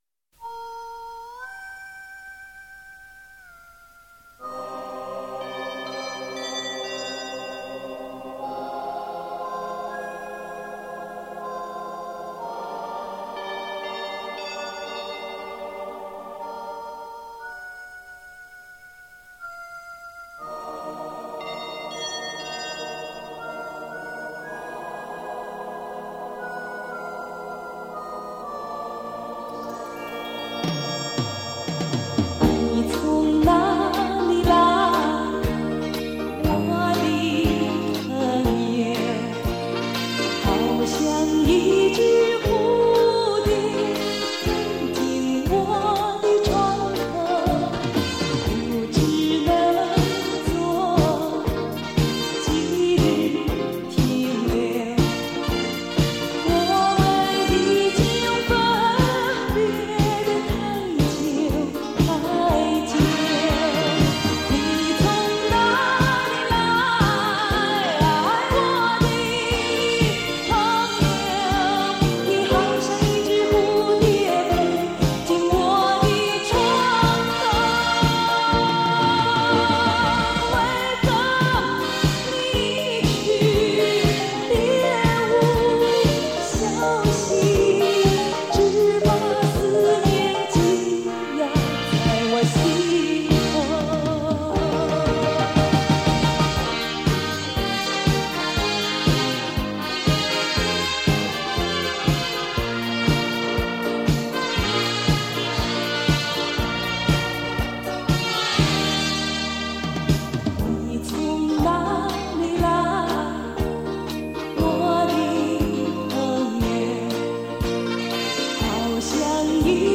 她那有别于其他歌星，富有独特韵味的歌声，为广大听众留下了十分难忘的印象。